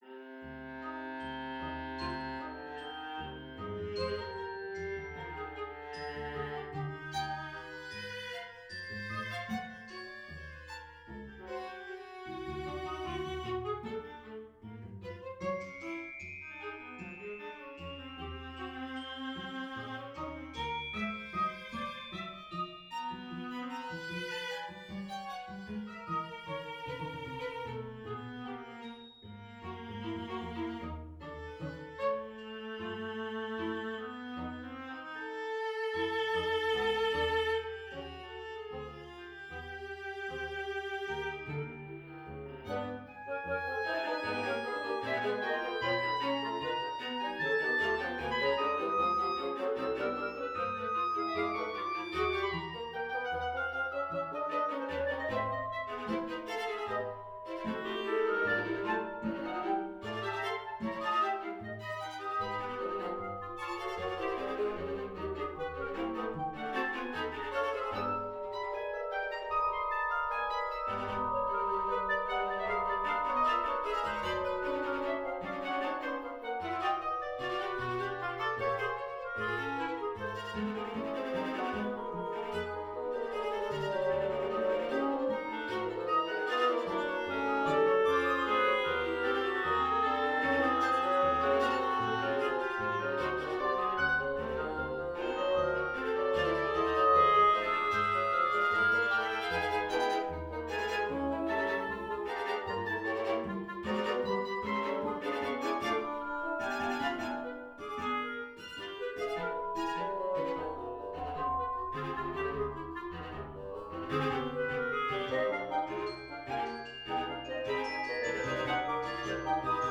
Symphonic Orchestra, Chamber Music, Classical style